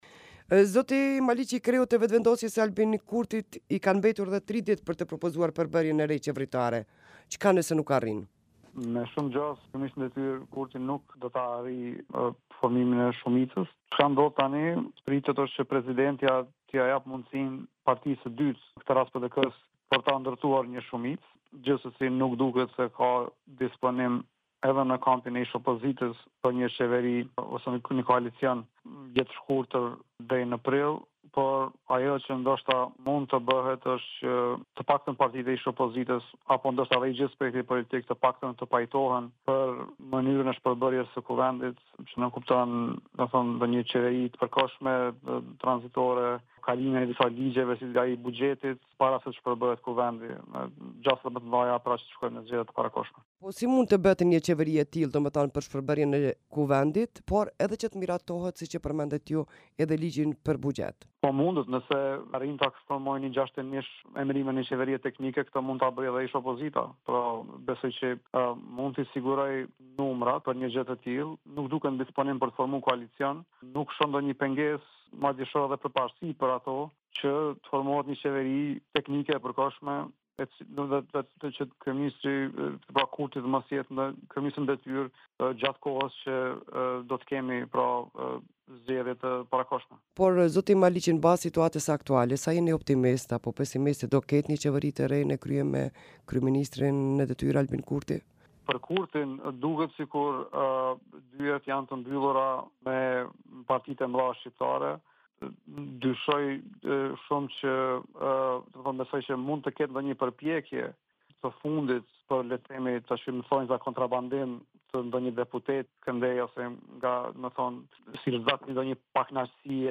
Në një intervistë për Radion Evropa e Lirë, ai parashikon që presidentja e vendit, Vjosa Osmani, do t’ia japë mandatin partisë së dytë fituese në zgjedhjet e 9 shkurtit, në këtë rast Partisë Demokratike, edhe pse partitë që në mandatin e kaluar ishin në opozitë nuk kanë shprehur ndonjë interesim për krijimin e qeverisë së re. Prandaj, sipas tij, gjasat më të mëdha janë që vendi të shkojë në zgjedhje të parakohshme.